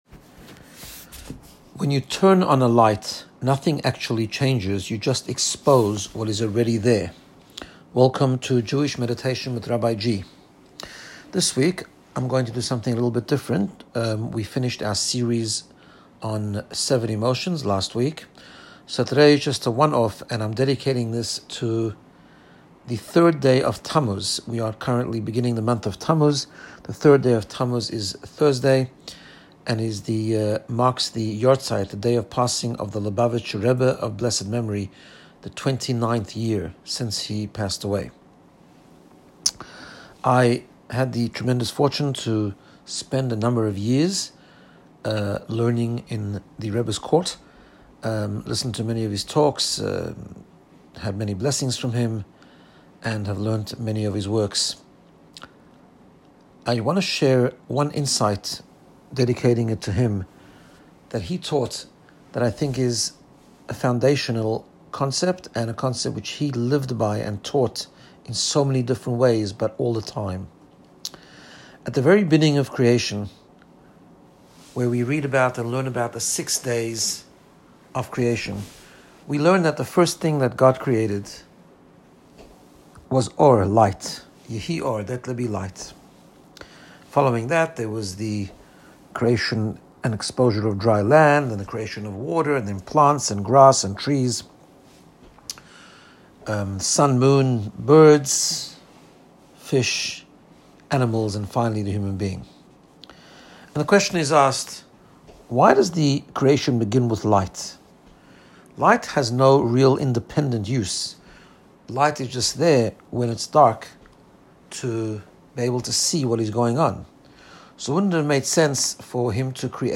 Meditation-Korach.m4a